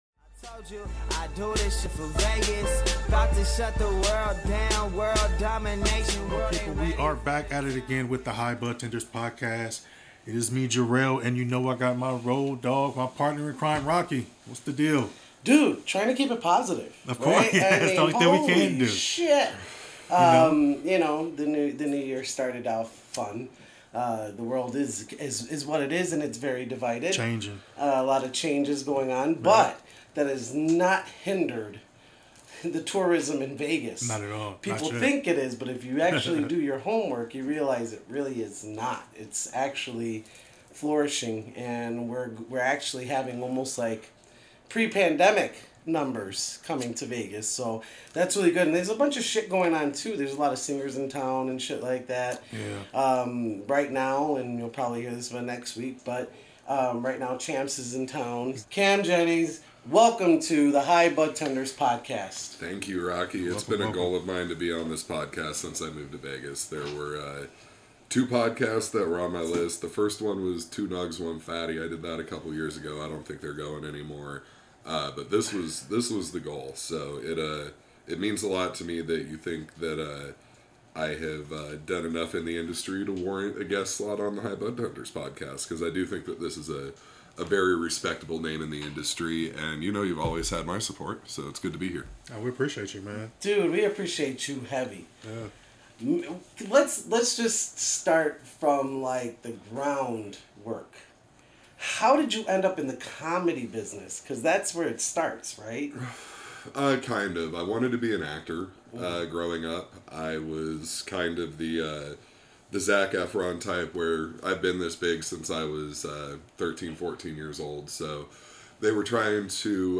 Be a guest on this podcast Language: en Genres: Business , Careers , Comedy , Comedy Interviews Contact email: Get it Feed URL: Get it iTunes ID: Get it Get all podcast data Listen Now...